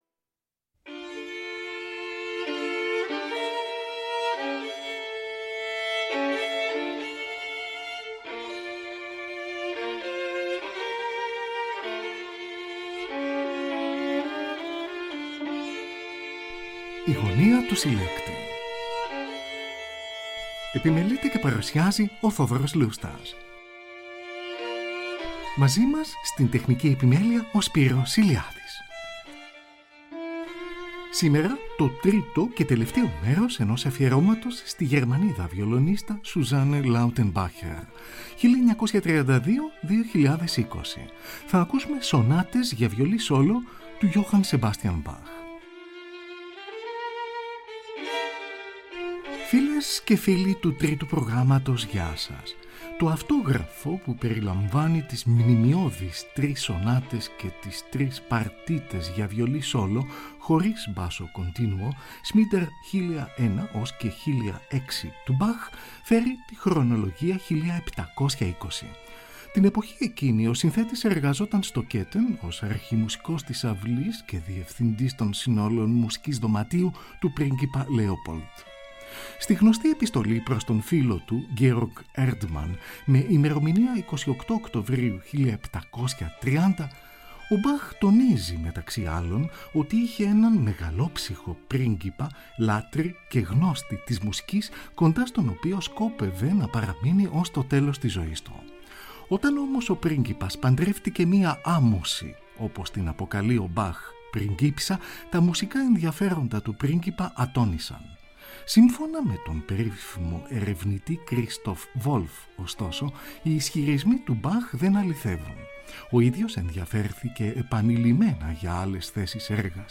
Βιολι Stradivarius